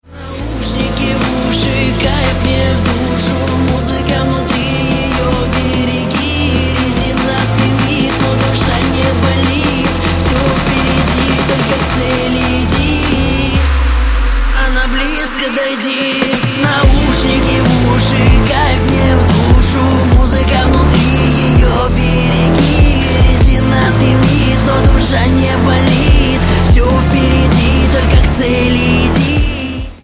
Громкие Рингтоны С Басами
Рэп Хип-Хоп Рингтоны